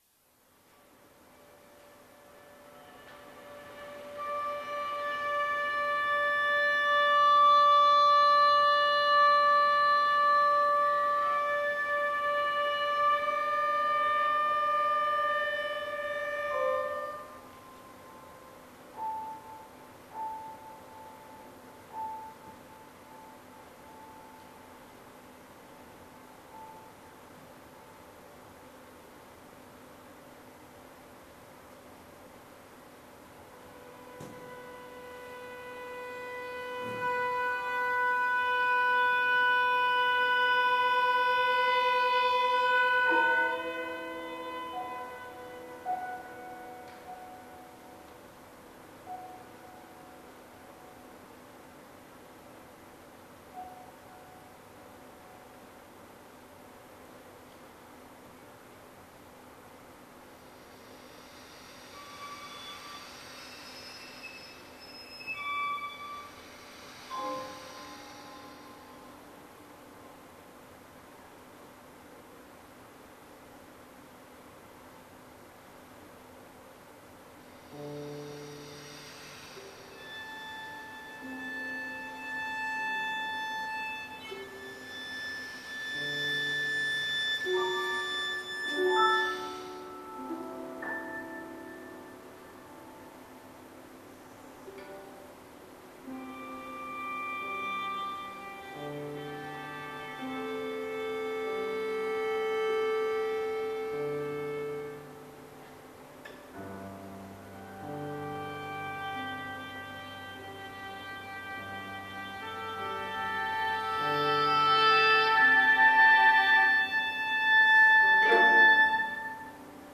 Summer grass, Autumn wind premiere
for oboe, viola, and piano